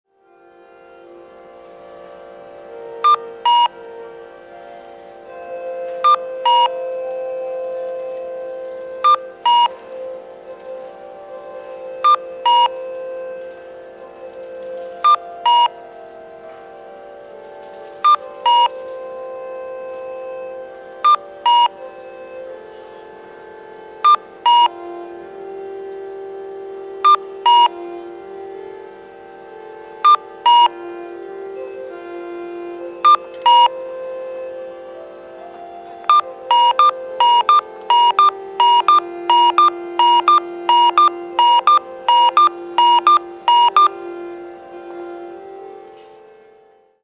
交通信号オンライン｜音響信号を録る旅｜大分県の音響信号｜[大分中央:0055]中央通り
中央通り(大分県大分市)の音響信号を紹介しています。